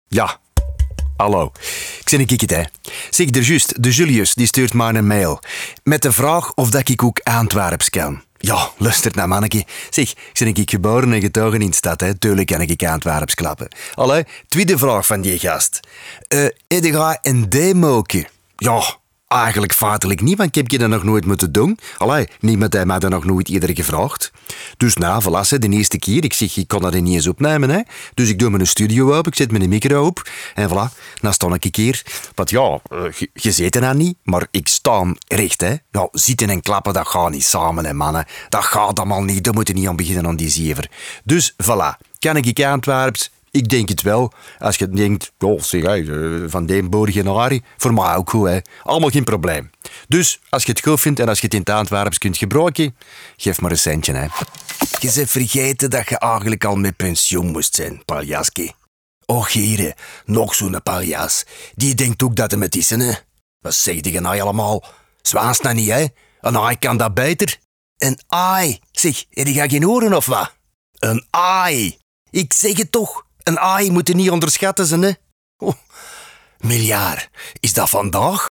Cálida, Profundo, Seguro, Maduro, Accesible